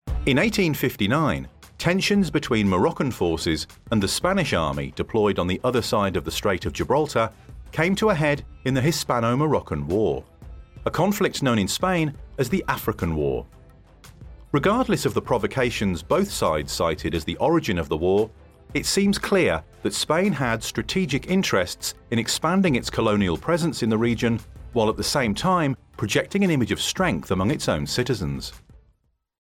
English (British)
Audio Guides
Mic: SHURE SM7B